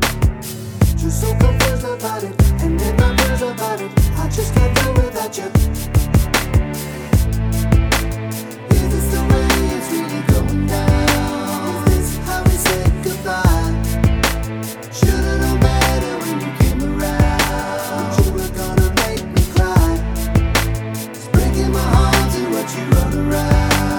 no Backing Vocals R'n'B / Hip Hop 5:22 Buy £1.50